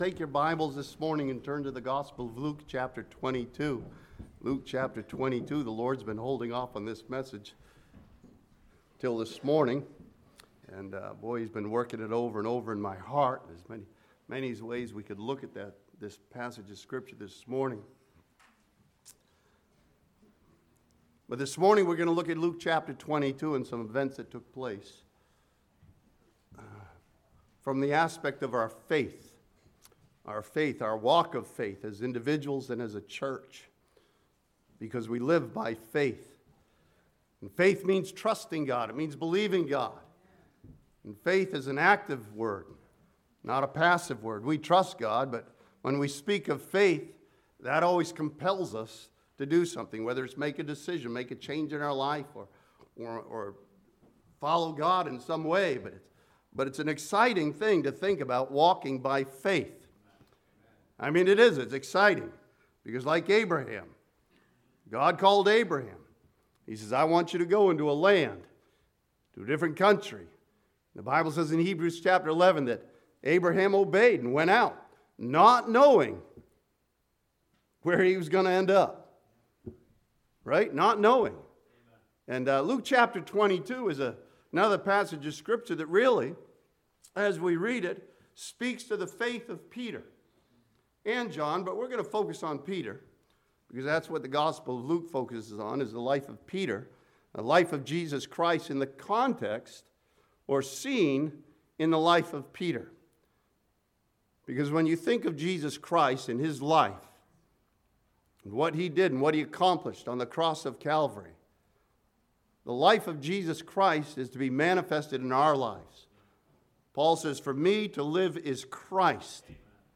This sermon from Luke chapter 22 challenges believers to take steps of faith and follow God's leading.